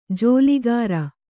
♪ jōligāra